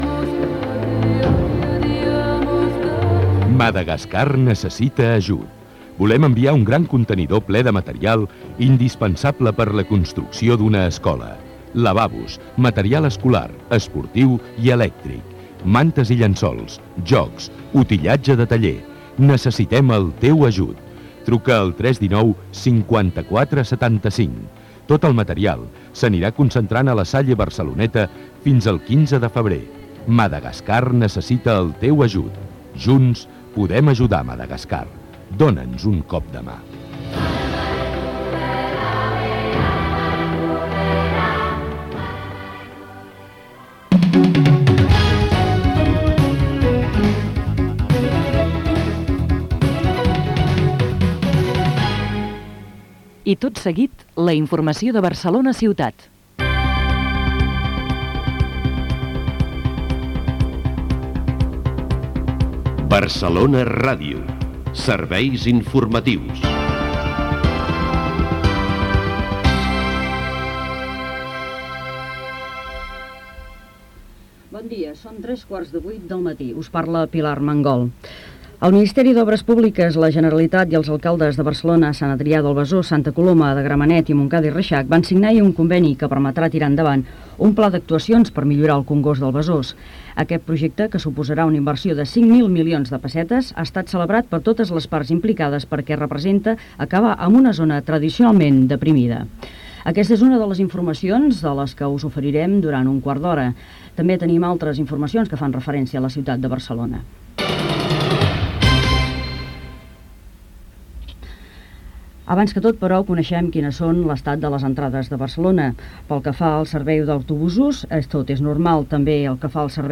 sintonia de COM Ràdio, identificació de la ràdio. Notícies: millora del congost del Besòs, informació de l'estat del transport i de la circulació
Gènere radiofònic Informatiu